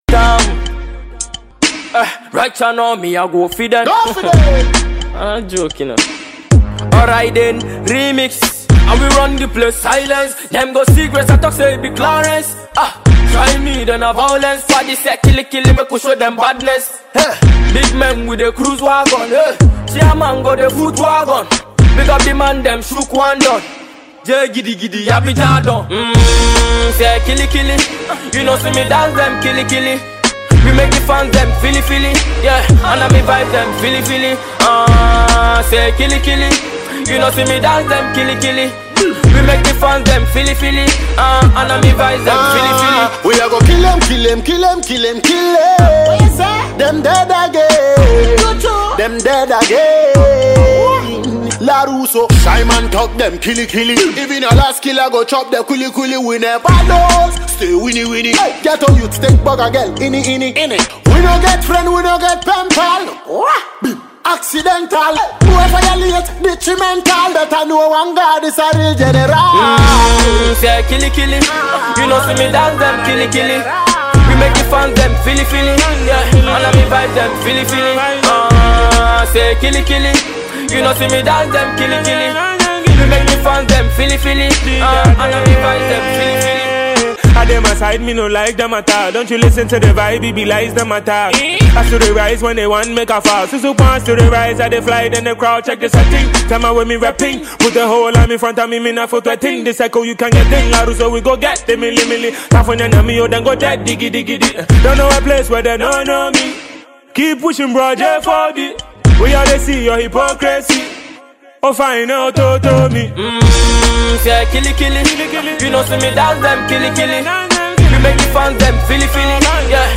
Dancehall prodigy